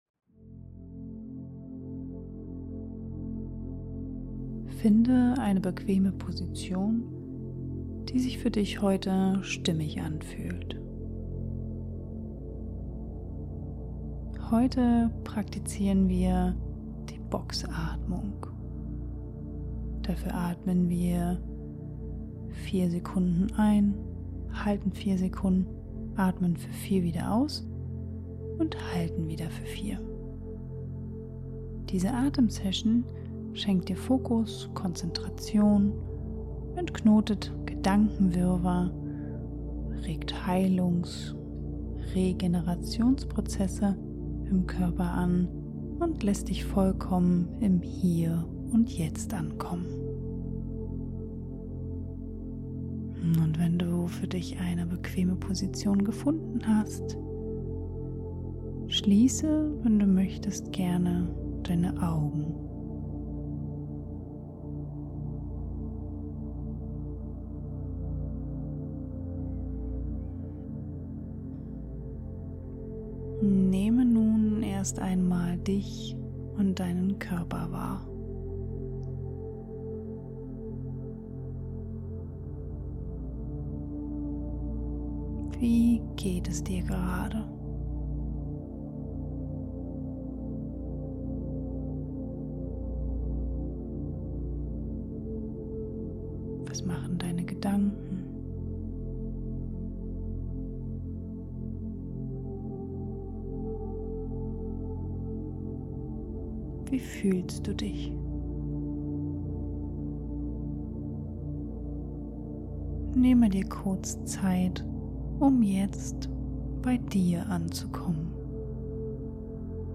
In dieser Folge leite ich dich durch eine sanfte Boxatmung, die dir hilft,  deine Gedanken zu sortieren  dein Nervensystem zu beruhigen  wieder klarzukommen Setze dich nicht unter Druck und versuche krampfhaft, die Gedanken still werden zu lassen.